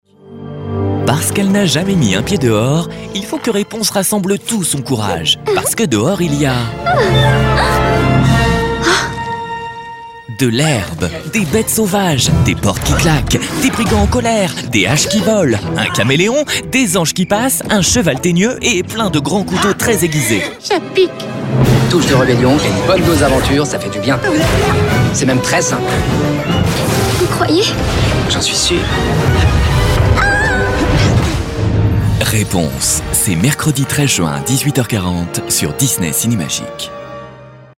DISNEY CINEMAGIC comédie - Comédien voix off
Genre : voix off.